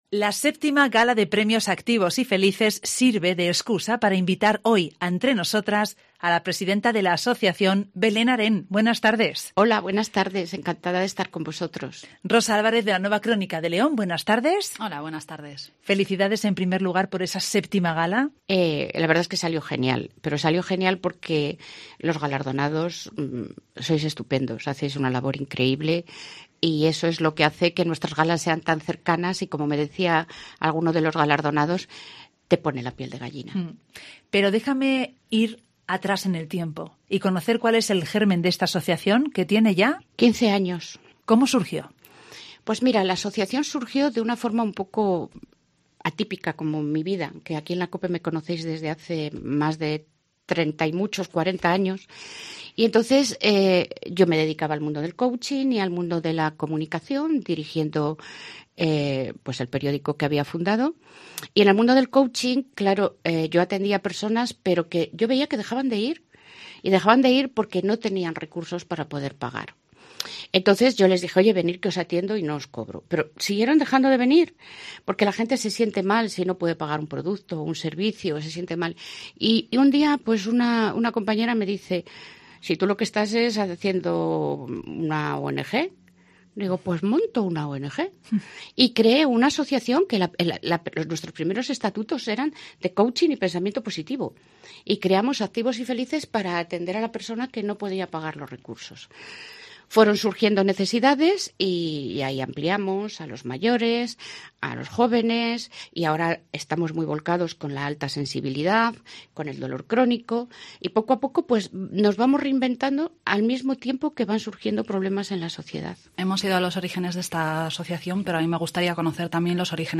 El espacio "Entre Nosotras" pretender poner en primer plano a los protagonistas de la actualidad social, cultural, política, económica y deportiva de la provincia de León, con entrevistas que se emiten en Mediodía en COPE León todos los miércoles a las 13:50 h. La Nueva Crónica de León colabora con este espacio y difunde la entrevista al día siguiente, publicándola en el periódico, tanto en papel como en formato digital.